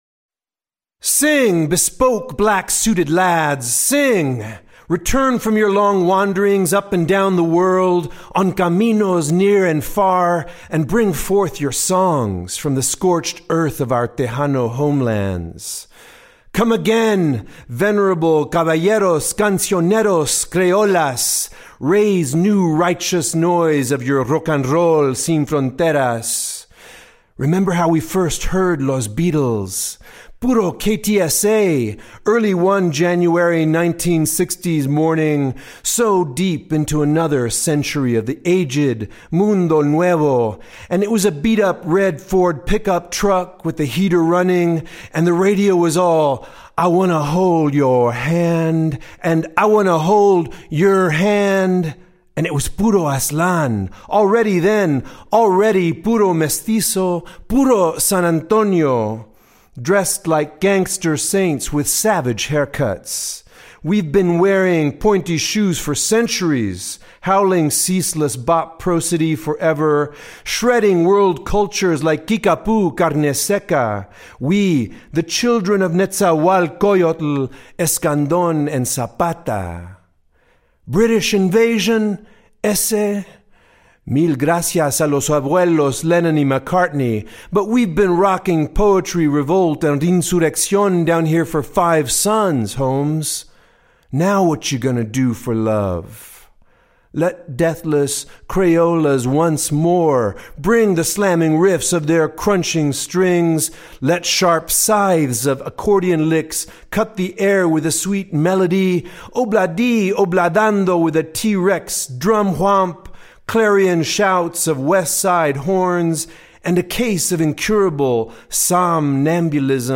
Liner notes read